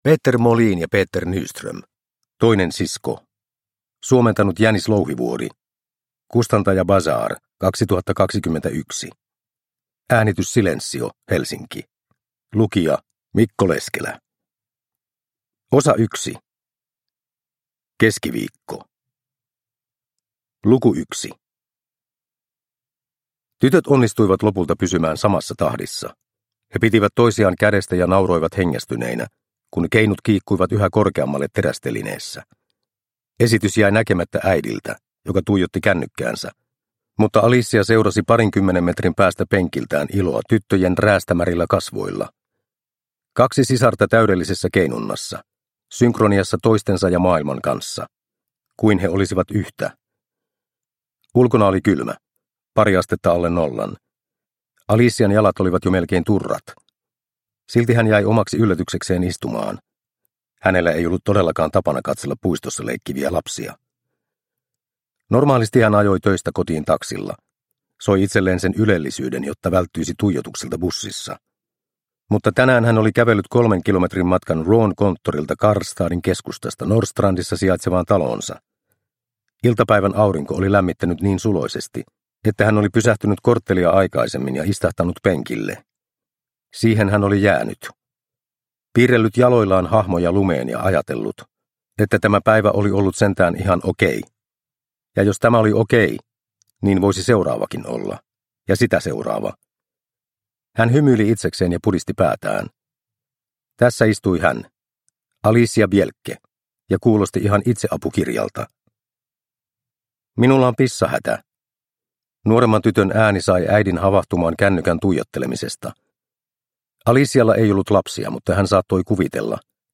Toinen sisko – Ljudbok – Laddas ner